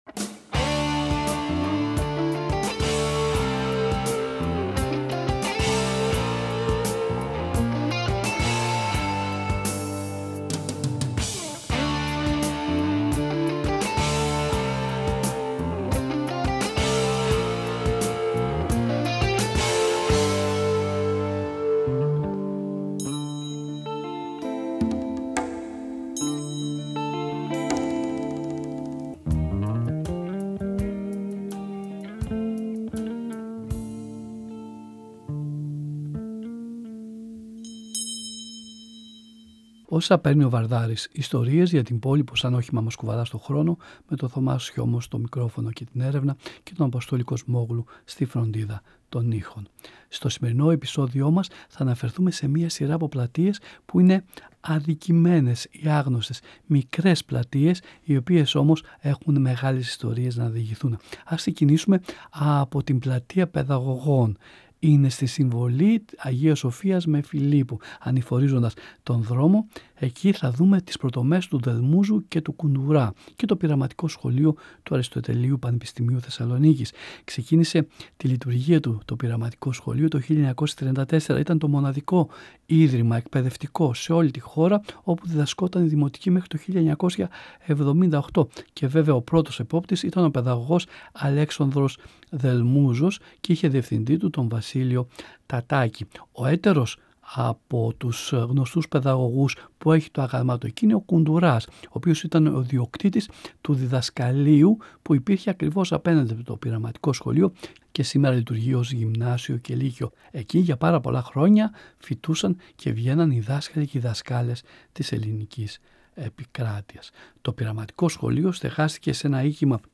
Αφηγήσεις, ιστοριες, μικρές και μεγάλες λεπτομέρειες για την πόλη που σαν όχημα μας κουβαλά στον χρόνο.